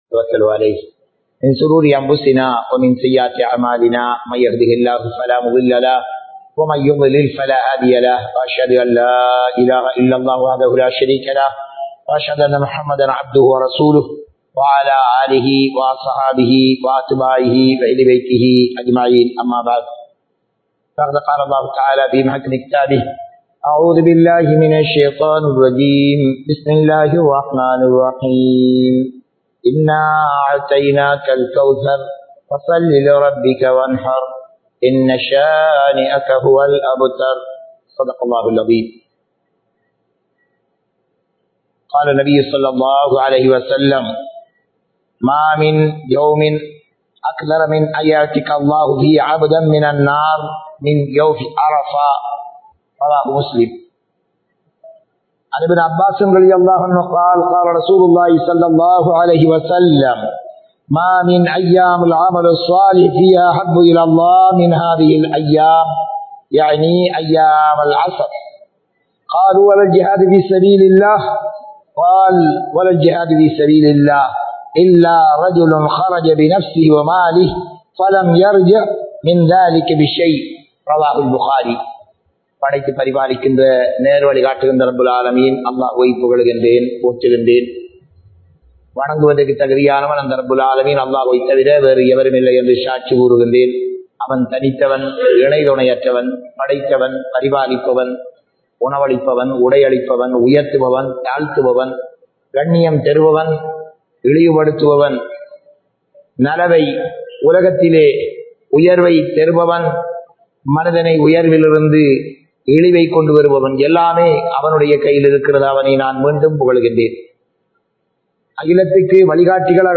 துல்ஹஜ்ஜூடைய அமல்கள் (Deeds on the Holy Month of Dhul hajj) | Audio Bayans | All Ceylon Muslim Youth Community | Addalaichenai
Thaikka Nagar Jumua Masjith